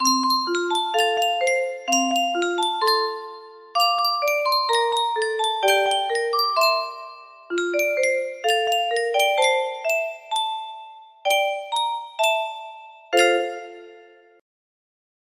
Yunsheng Music Box - Unknown Tune 2370 music box melody
Full range 60